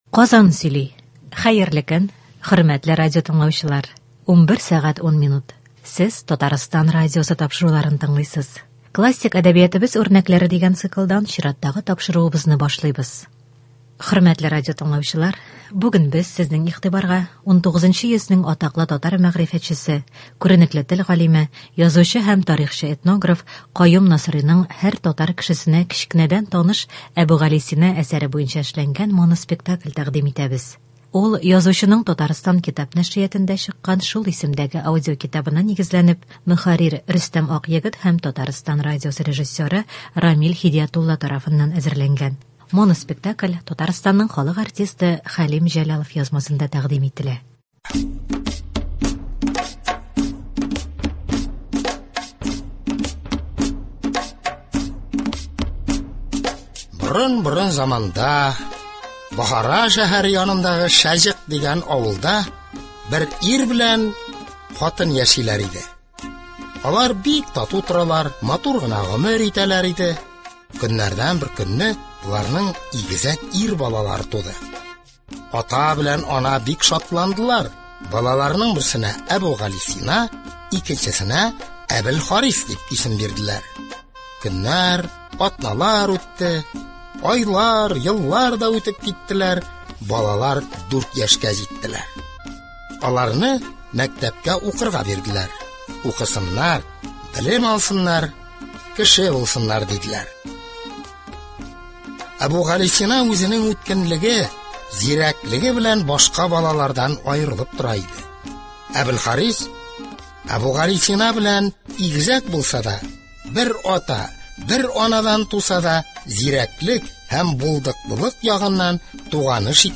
Без сезнең игътибарга 19 нчы йөзнең атаклы татар мәгърифәтчесе, күренекле тел галиме, язучы һәм тарихчы-этнограф Каюм Насыйриның һәр татар кешесенә кечкенәдән таныш “Әбүгалисина” әсәре буенча эшләнгән моноспектакль тәкъдим итәбез.